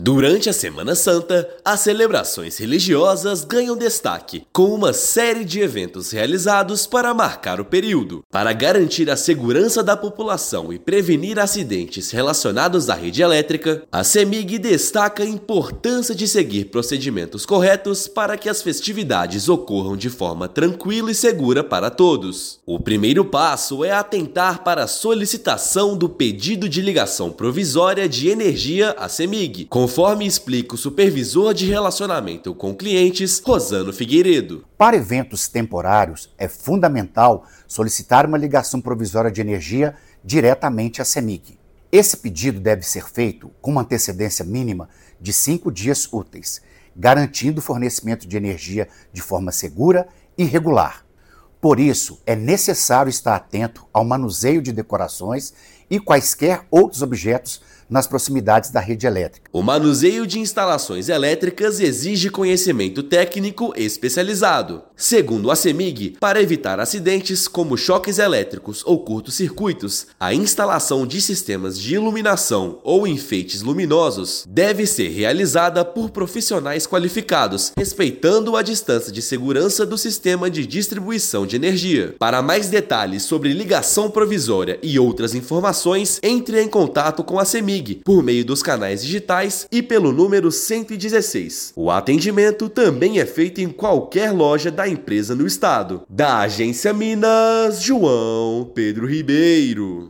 Companhia orienta sobre montagem de som, sistemas de luz, enfeites e palanques. Ouça matéria de rádio.